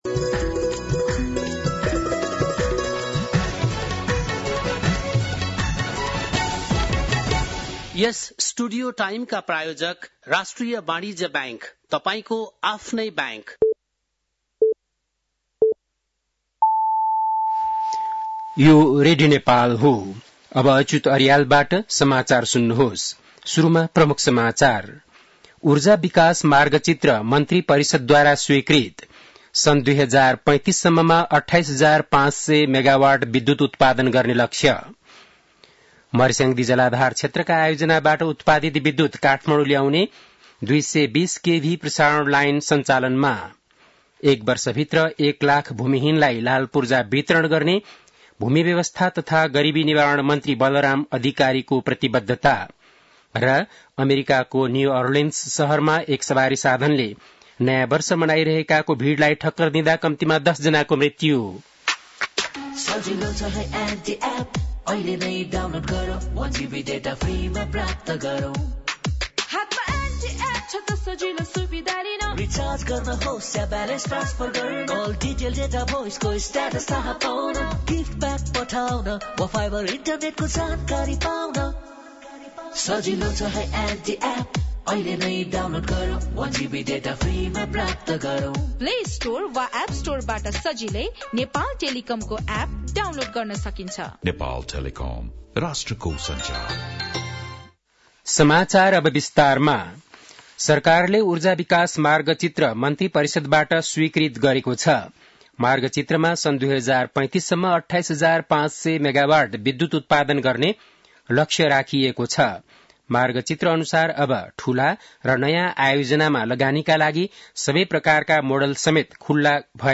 बेलुकी ७ बजेको नेपाली समाचार : १८ पुष , २०८१